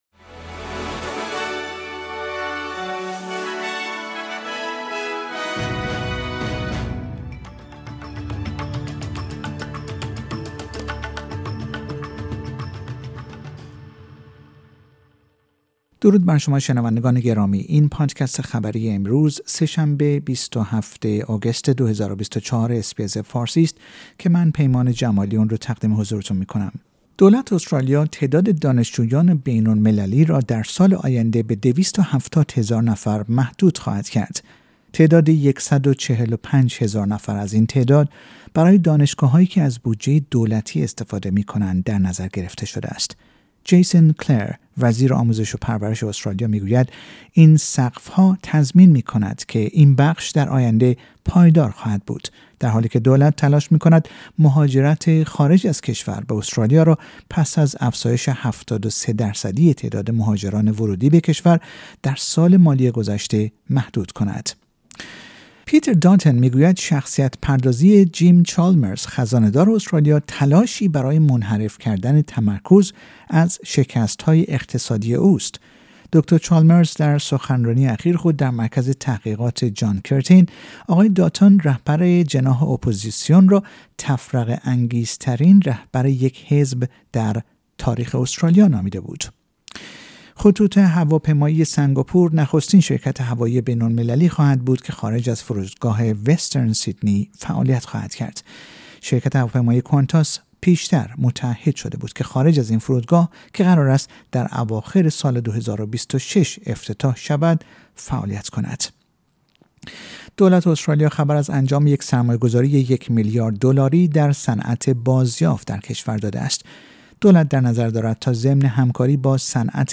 در این پادکست خبری مهمترین اخبار استرالیا در روز سه شنبه ۲۷ آگوست ۲۰۲۴ ارائه شده است.